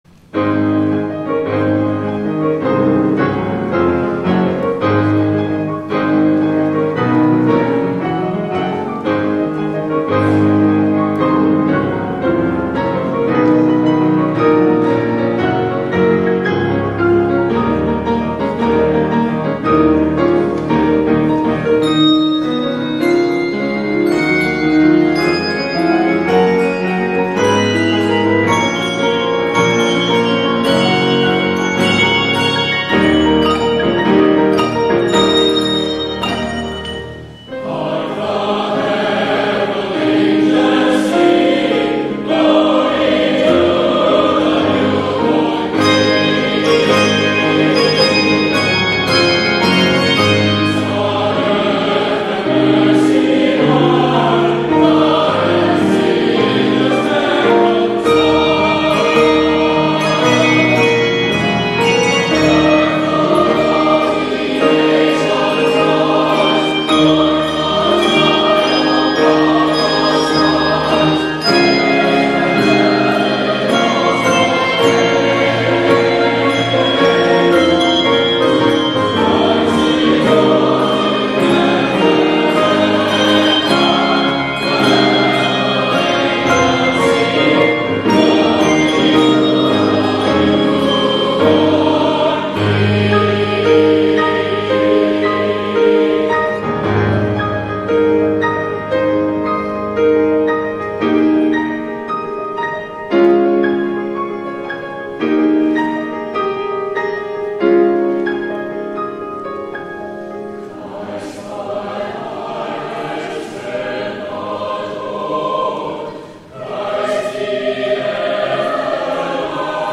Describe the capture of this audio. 8 P.M. WORSHIP